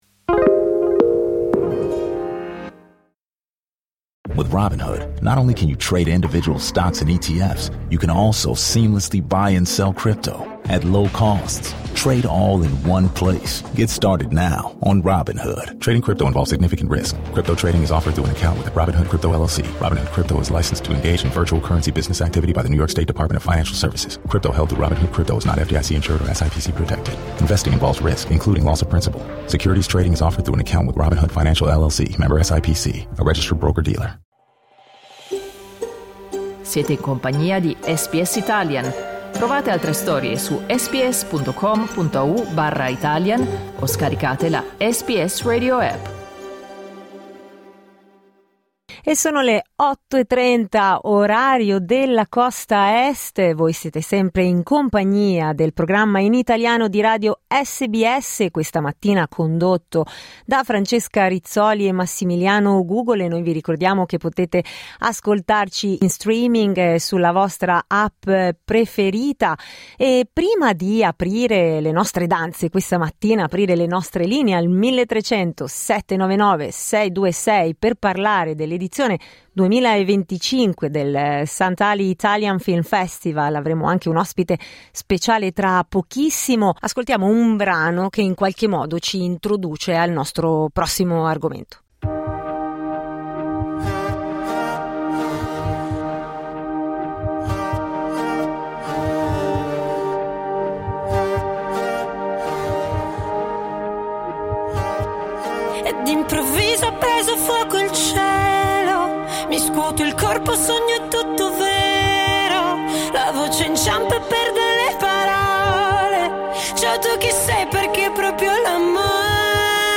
Nella conversazione di oggi abbiamo coinvolto anche gli ascoltatori e le ascoltatrici, chiedendo se parteciperanno all’edizione del Festival di quest’anno e quali siano i titoli che hanno trovato più interessanti.
talkback